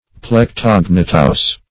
Meaning of plec-tognathous. plec-tognathous synonyms, pronunciation, spelling and more from Free Dictionary.
Search Result for " plec-tognathous" : The Collaborative International Dictionary of English v.0.48: Plectognathic \Plec`tog*nath"ic\, Plec-tognathous \Plec-tog"na*thous\, a. (Zool.)